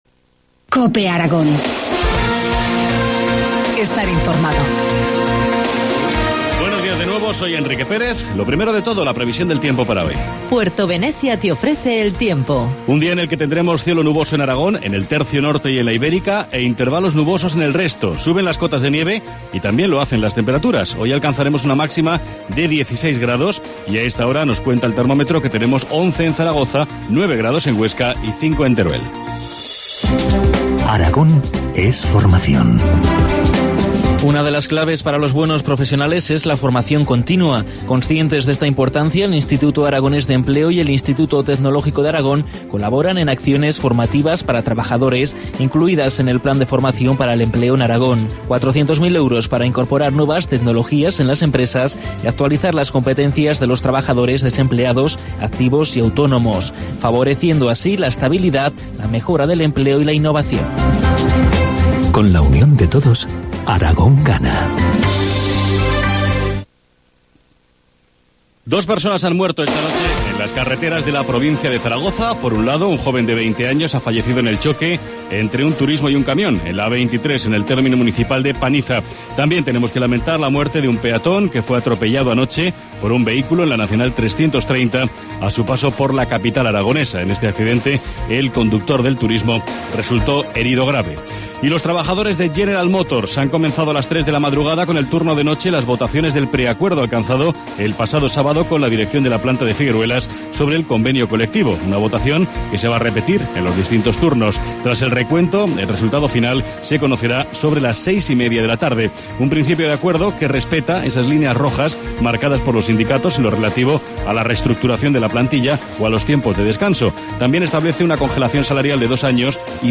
Informativo matinal, lunes 8 de abril, 8.25 horas